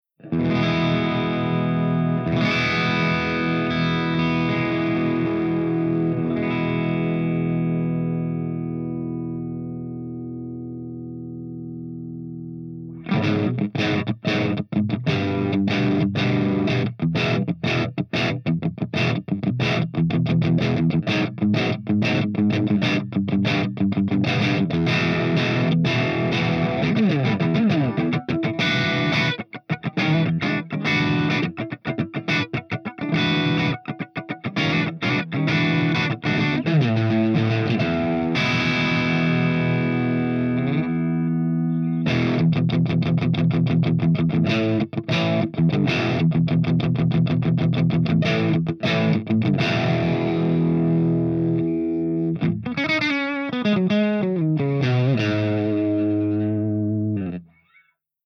090_PEAVEY_CRUNCHDRIVE_GB_SC-1.mp3